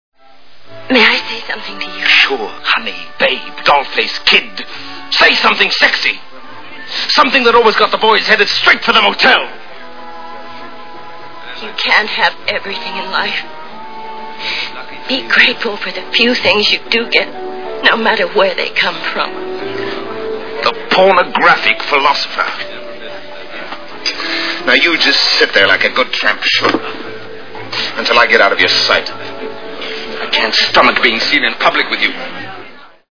Butterfield 8 Movie Sound Bites